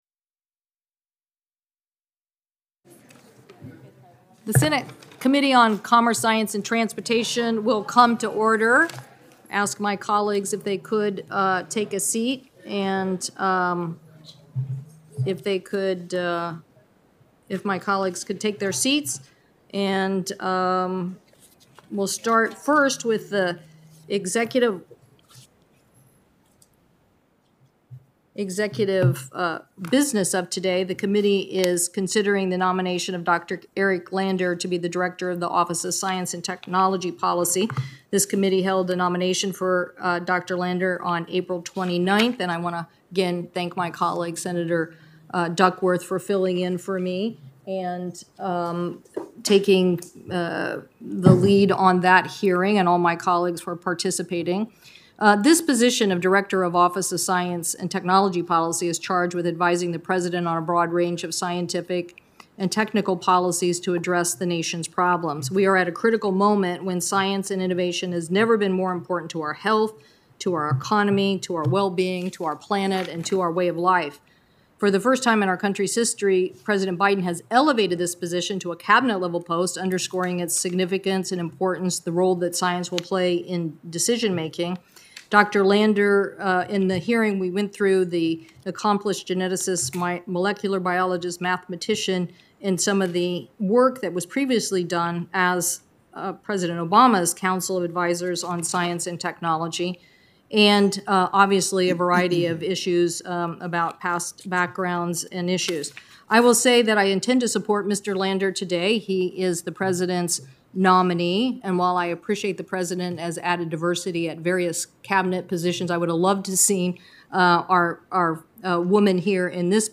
WASHINGTON, D.C.—Today, the Senate Committee on Commerce, Science, and Transportation approved President Biden’s nomination of Dr. Eric S. Lander to be Director of the Office of Science and Technology Policy (OSTP) by voice vote. U.S. Senator Maria Cantwell (D-WA), the Chair of the committee, spoke about the responsibilities and importance of this Cabinet role, and stressed the need for increased diversity in STEM: